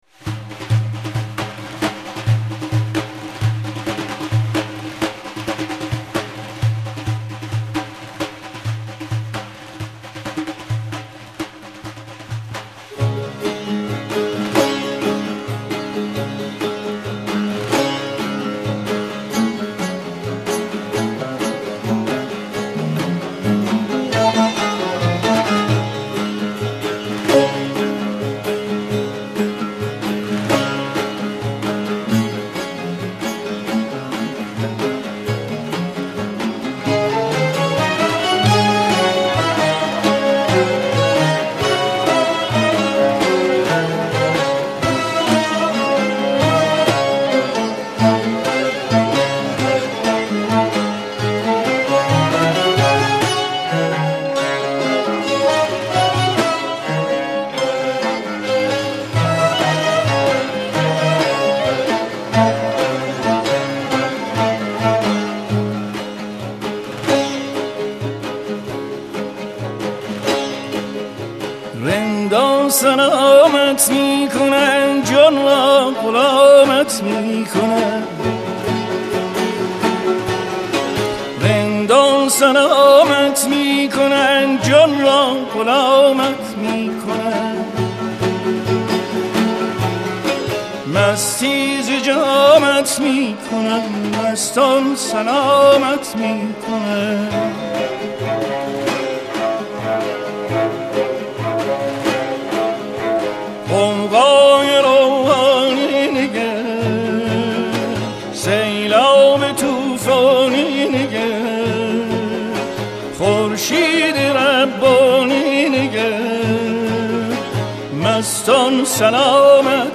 مجموعه آثار آوازی در دستگاه همایون